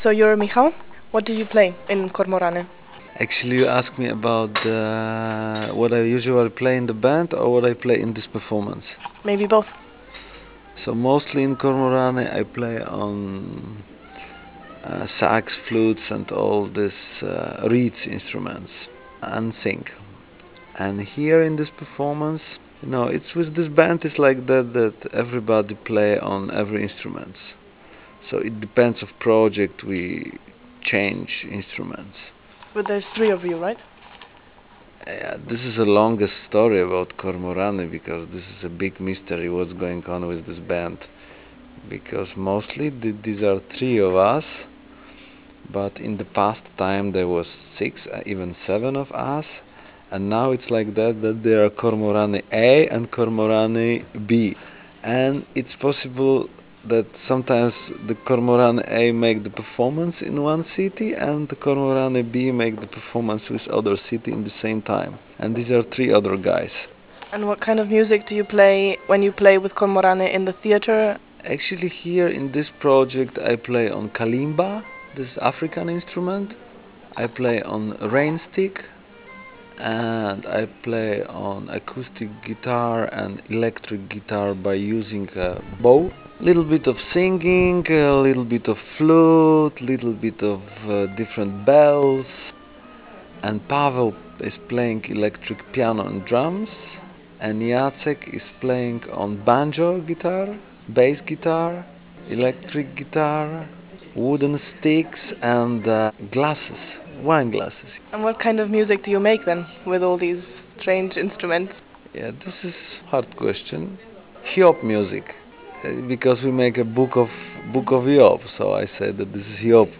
Interview -